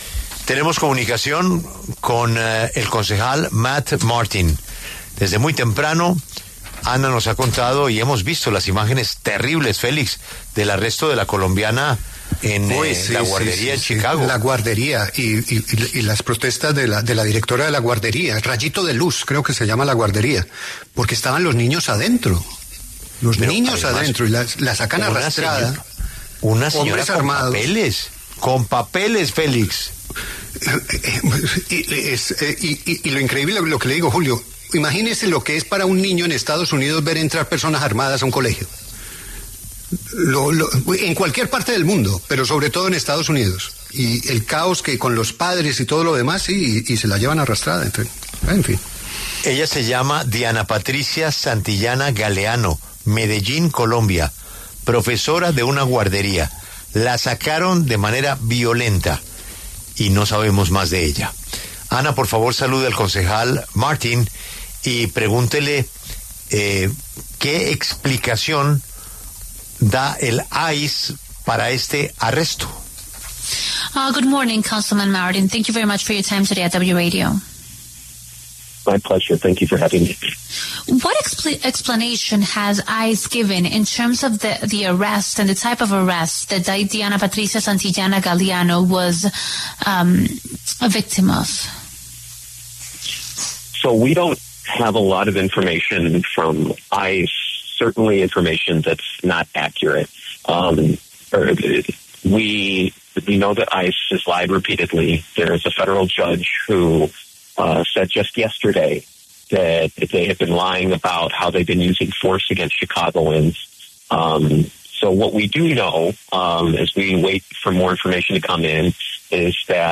El concejal Matt Martin, miembro del Ayuntamiento de Chicago para el distrito 47 de la ciudad, pasó por los micrófonos de La W para hablar sobre los hechos ocurridos en una guardería.